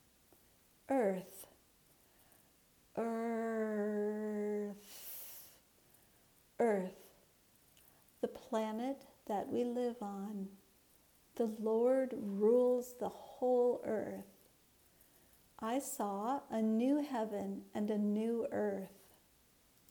/ɜːrθ/ (noun)